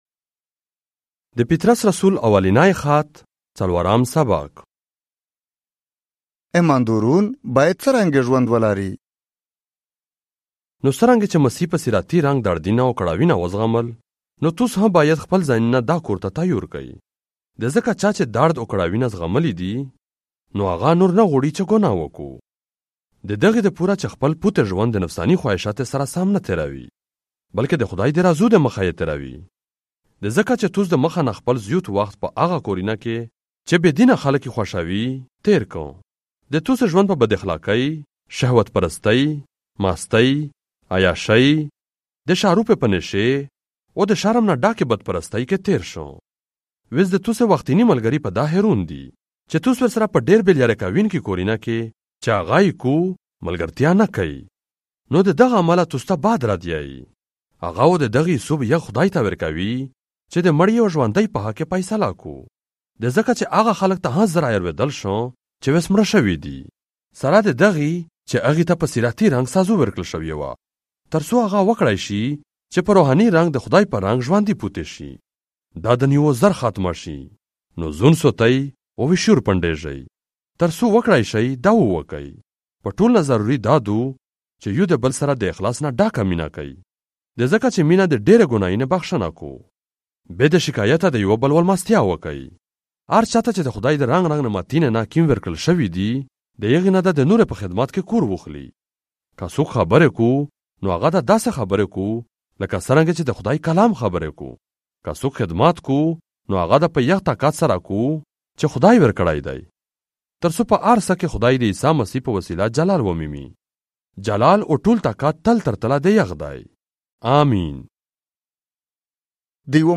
1 Peter - Chapter 4 in the Pashto language, Central - audio 2025